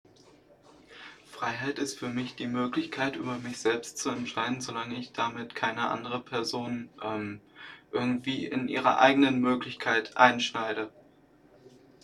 Standort der Erzählbox:
MS Wissenschaft @ Diverse Häfen
Standort war das Wechselnde Häfen in Deutschland.